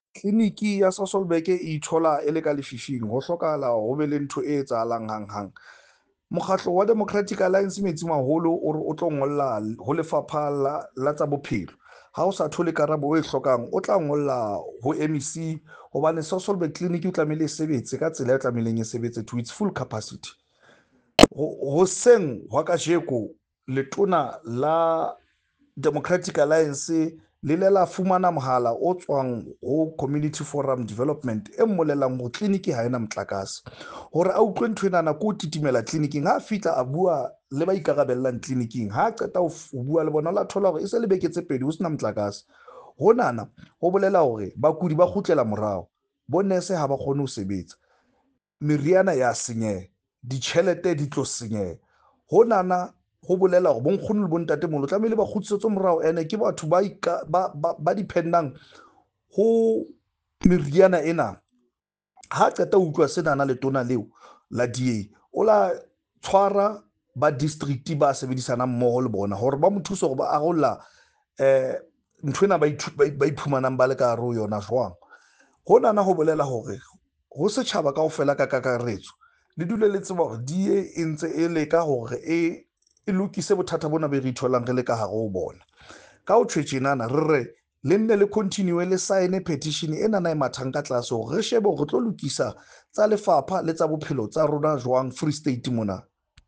Sesotho soundbites by Cllr Thabang Rankoe and